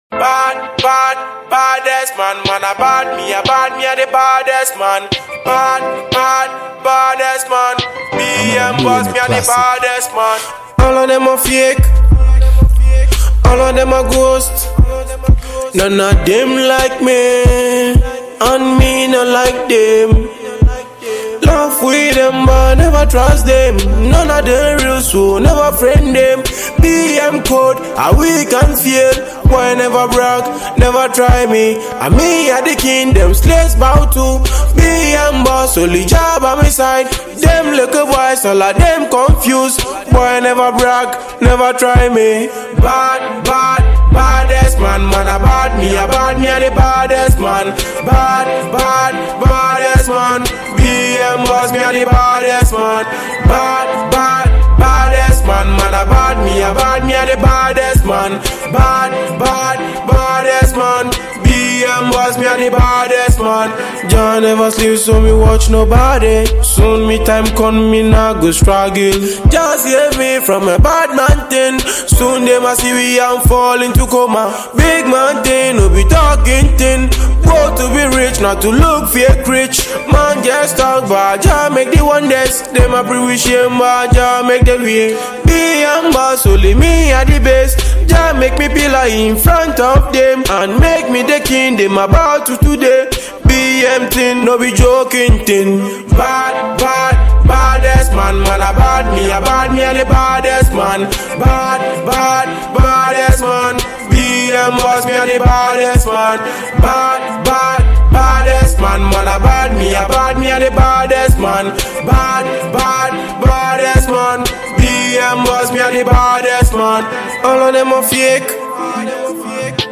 Reggae/Dancehall and Afrobeats